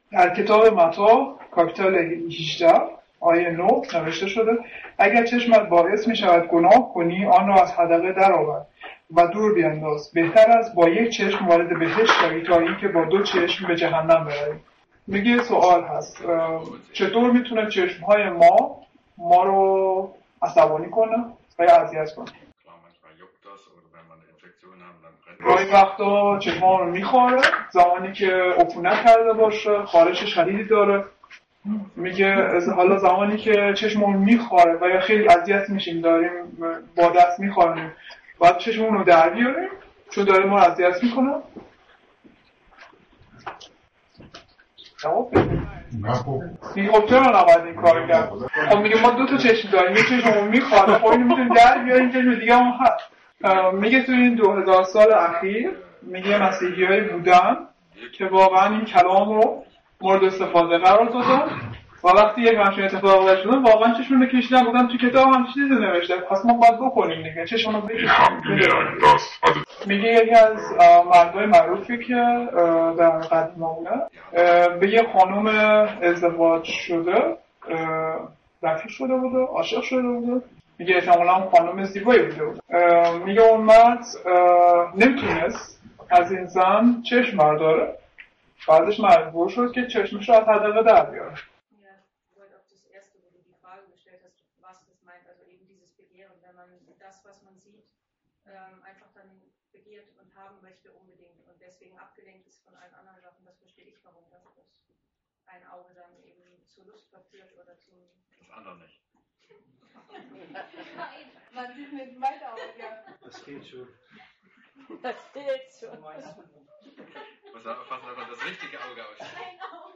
Übersetzung in Farsi